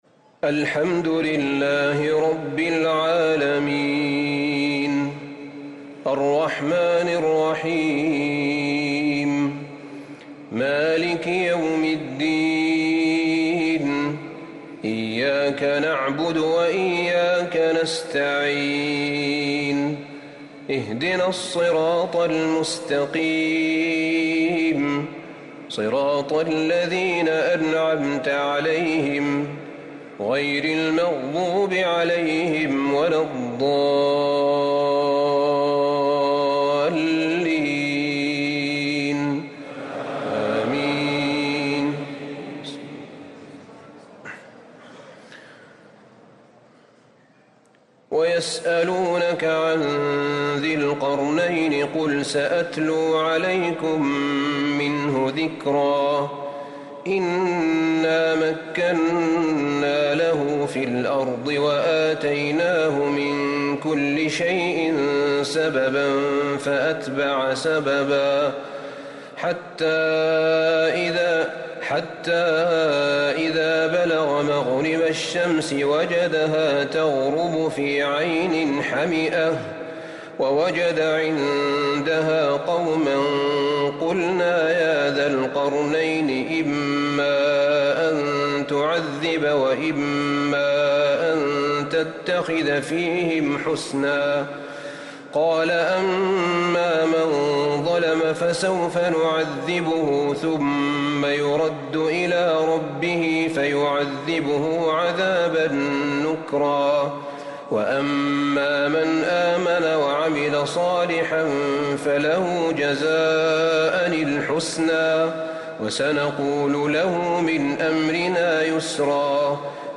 تراويح ليلة 21 رمضان 1444هـ من سورتي الكهف (83-110)و مريم (1-76) Taraweeh 21 th night Ramadan 1444H Surah Al-Kahf and Maryam > تراويح الحرم النبوي عام 1444 🕌 > التراويح - تلاوات الحرمين